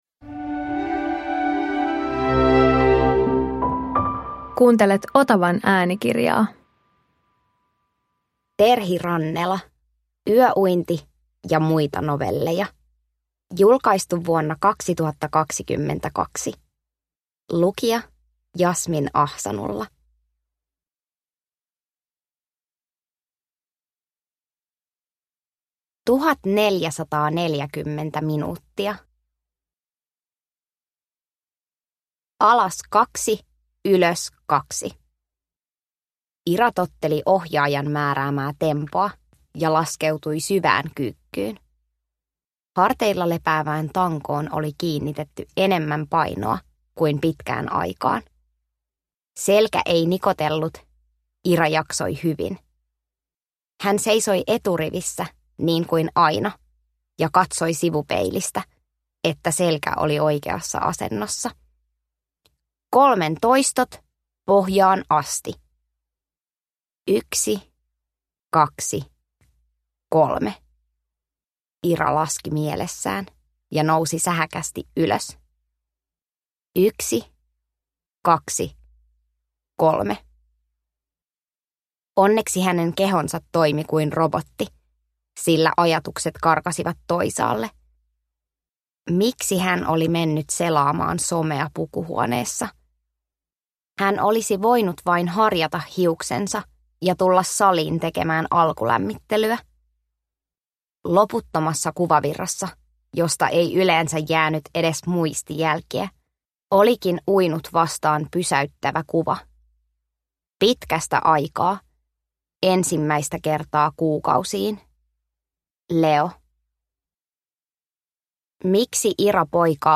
Yöuinti ja muita novelleja – Ljudbok